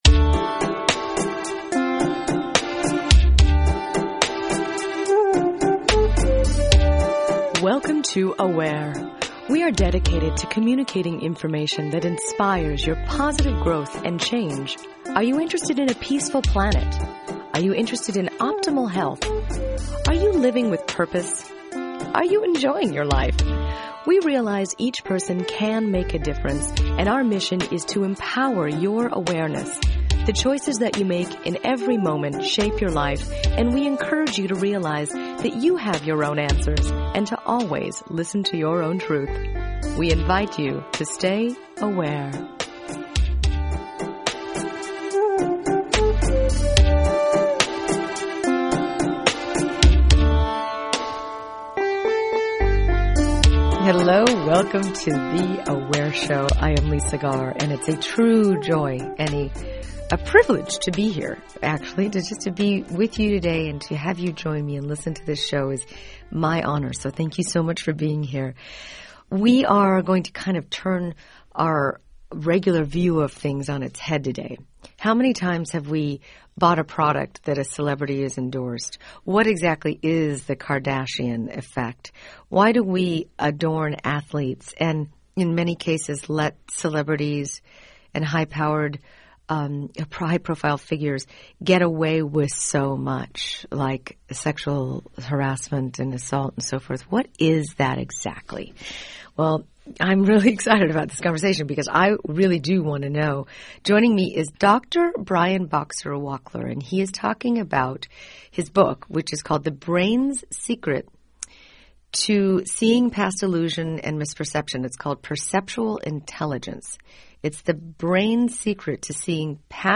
You’re going to discover a lot of insights that could potentially elevate your game during this very informative interview.